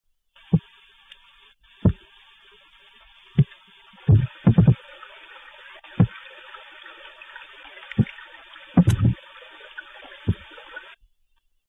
Type of sound produced grunts
Sound mechanism pharyngeal teeth stridulation amplified by adjacent swim bladder Behavioural context weak sounds during competitve feeding, louder under duress (netted, handled or with electrical stimulation)
Remark recording amplified by 3 dB, low pass nois reduction filter (< 3600 Hz) applied to recording